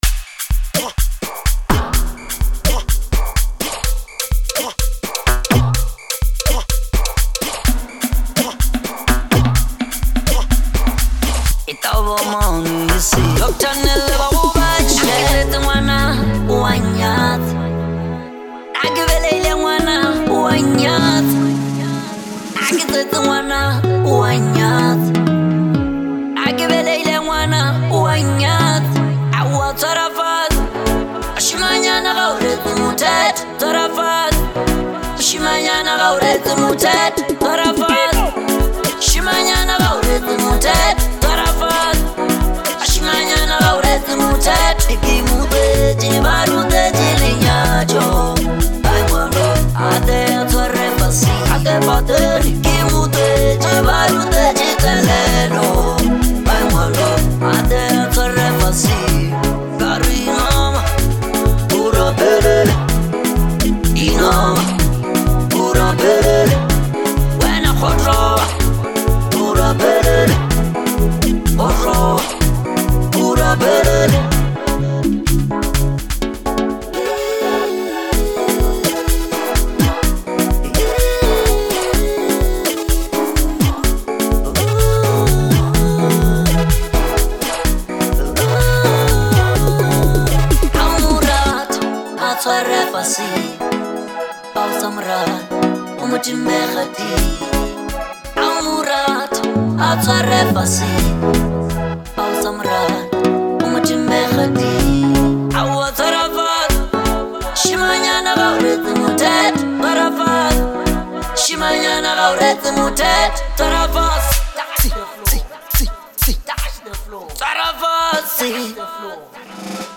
Genre: LEKOMPO.